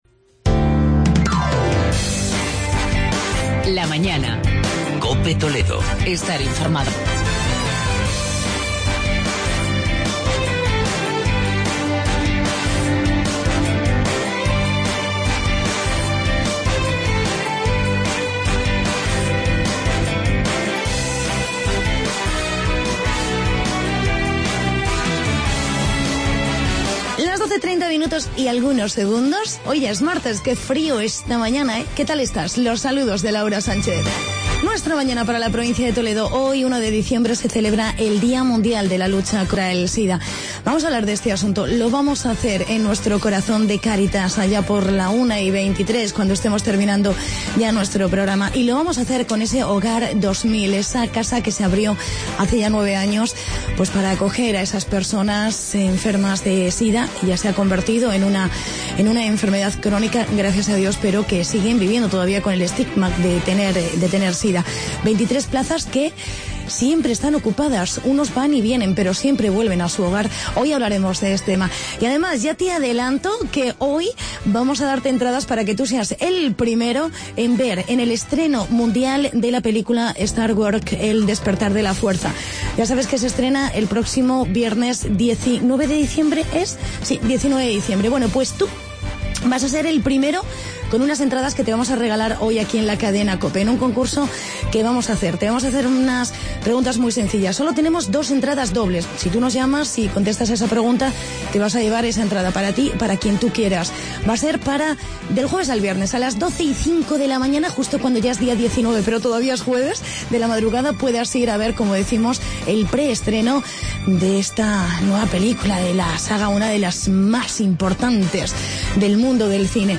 Concurso.